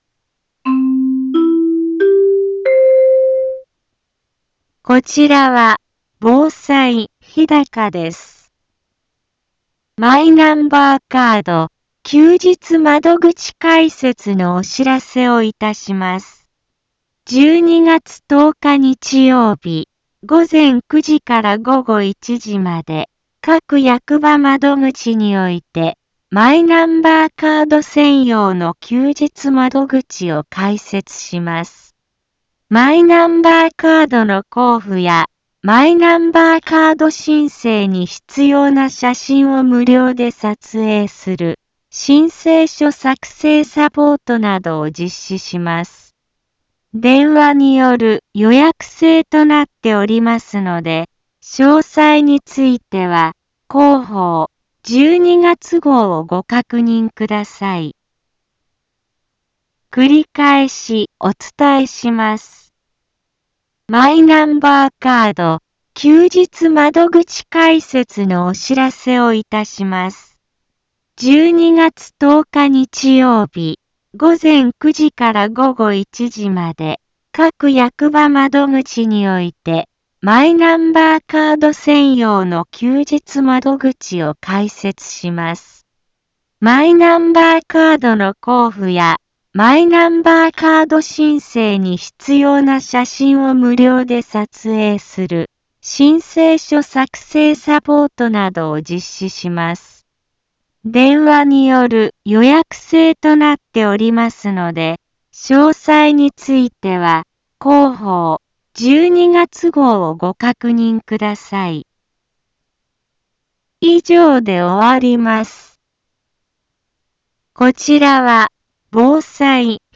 一般放送情報
Back Home 一般放送情報 音声放送 再生 一般放送情報 登録日時：2023-12-01 10:04:25 タイトル：マイナンバーカード休日窓口開設のお知らせ インフォメーション： マイナンバーカード休日窓口開設のお知らせをいたします。 12月10日日曜日、午前9時から午後1時まで、各役場窓口において、マイナンバーカード専用の休日窓口を開設します。